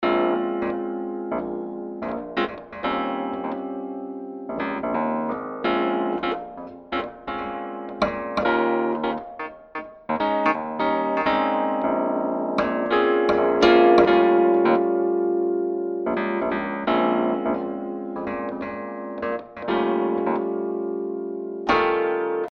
Sie stellt eine einzigartige Kombination aus Klaviatur, Hammermechanik und Zitherkorpus dar:
Die Dolceola – Antique: